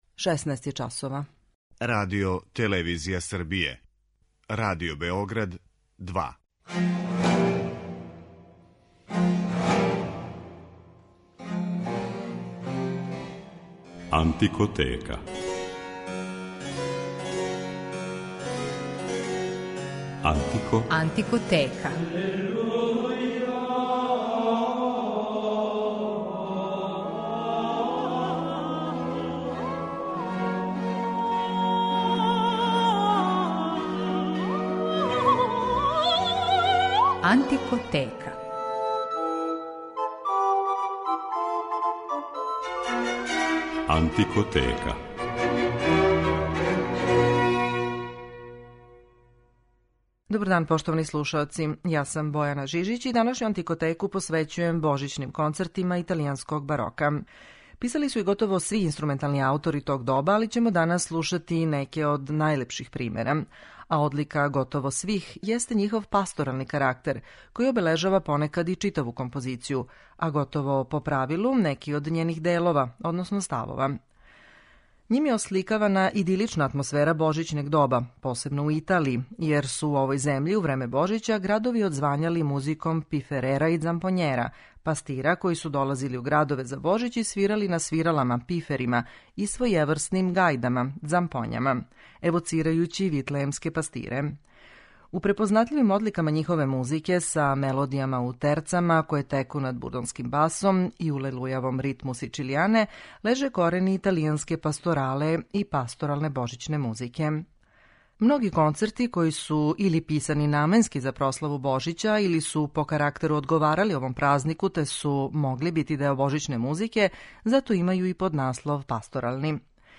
Божићна музика